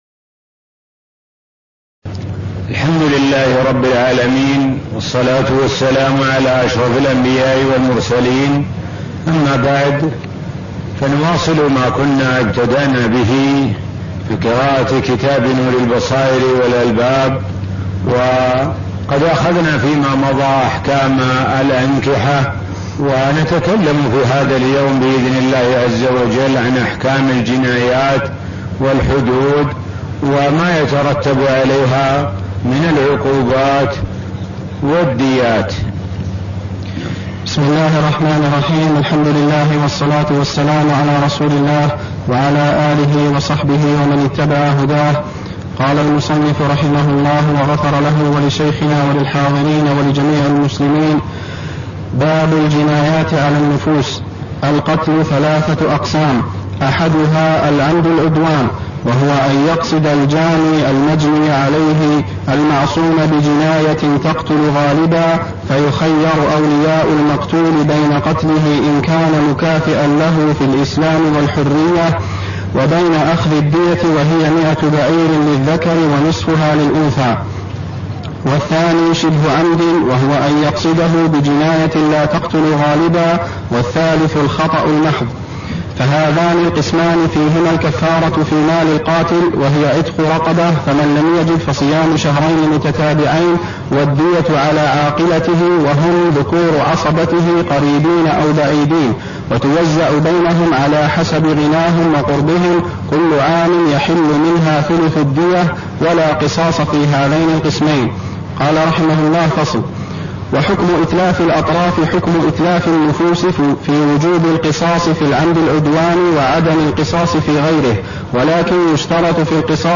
تاريخ النشر ٢١ رمضان ١٤٣١ هـ المكان: المسجد النبوي الشيخ: معالي الشيخ د. سعد بن ناصر الشثري معالي الشيخ د. سعد بن ناصر الشثري احكام الجنايات والحدود وما يترتب عليها من الديات (0001)